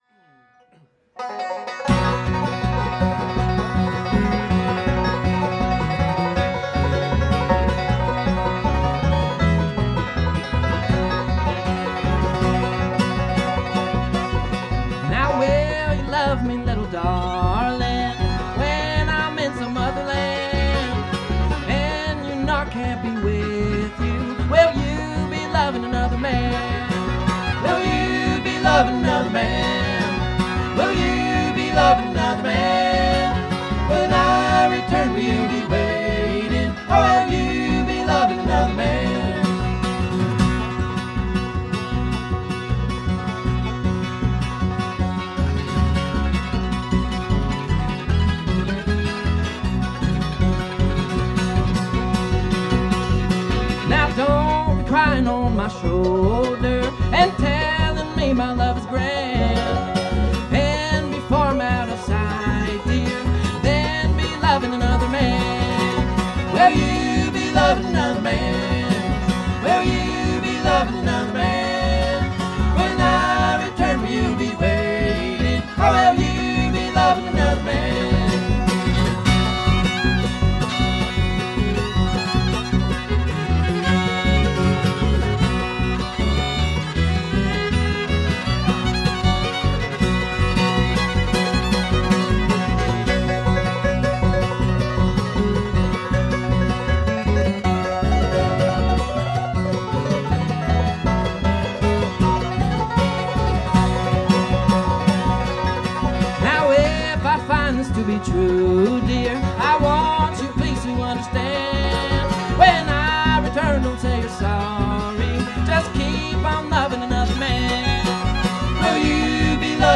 Tileworks of Bucks County Doylestown, PA
Mandolin
Guitar
Banjo
Fiddle/Vocals
Bass/Vocals